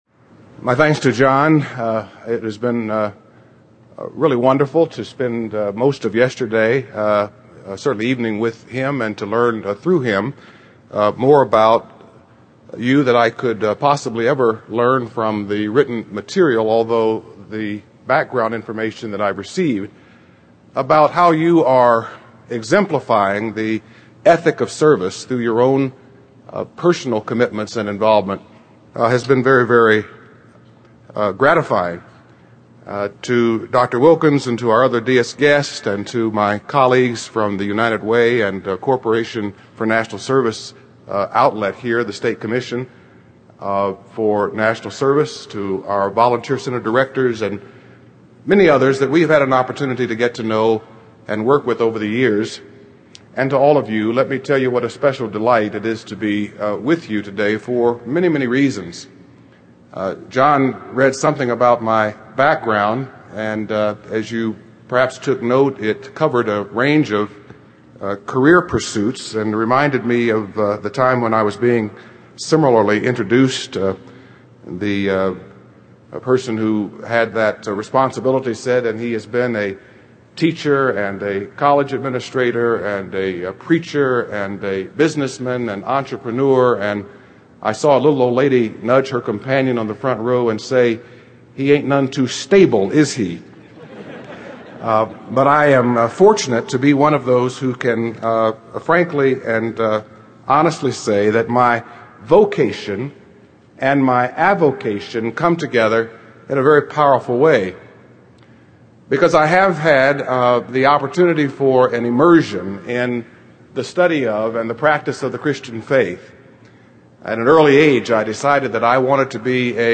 Forum